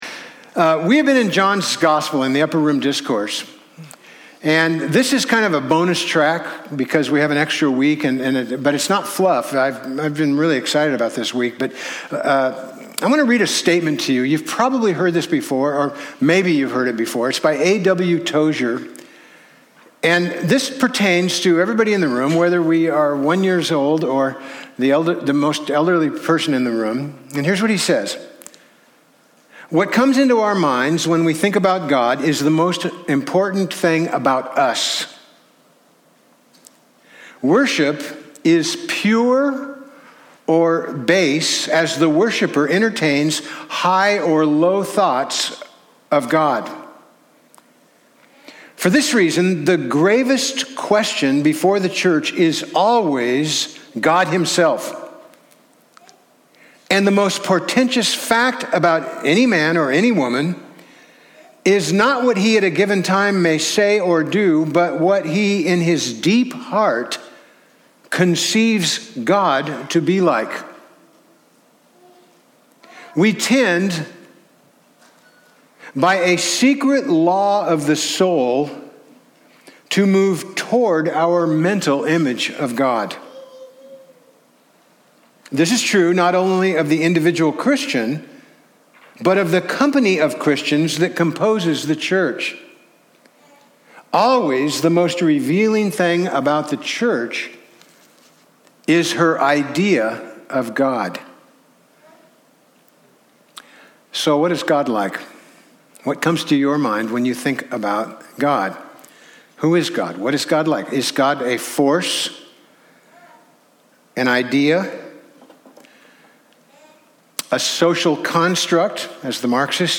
Passage: John 15 Service Type: Sunday